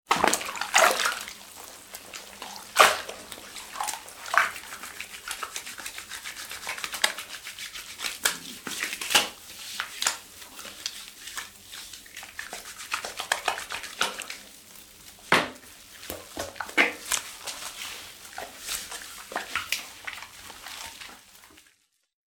Человек моется в ванной
Тут вы можете прослушать онлайн и скачать бесплатно аудио запись из категории «Человек, люди».